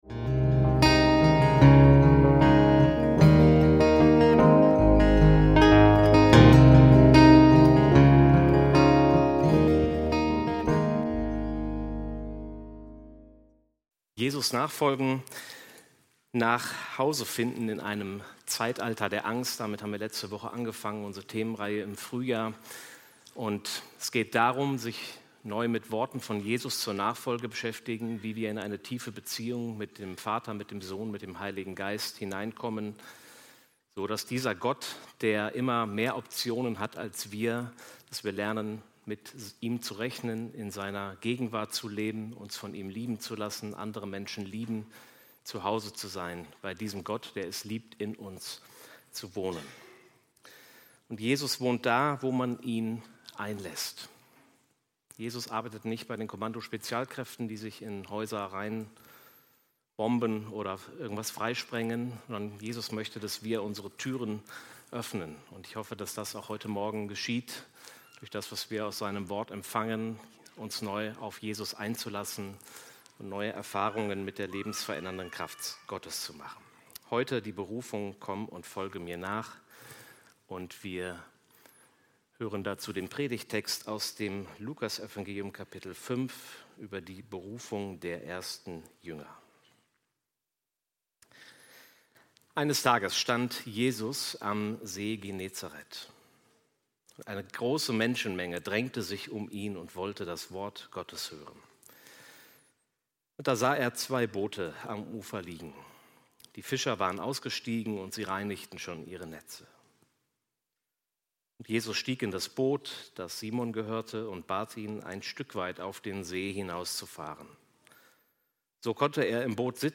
Die Berufung "Komm folge mir nach!" - Predigt vom 09.02.2025